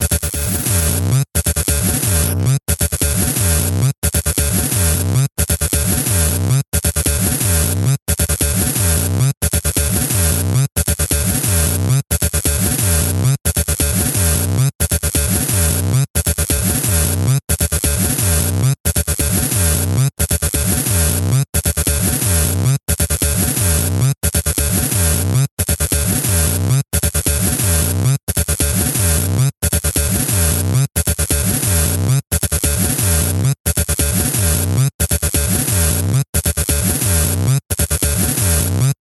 重低音が響くサウンド。
ループ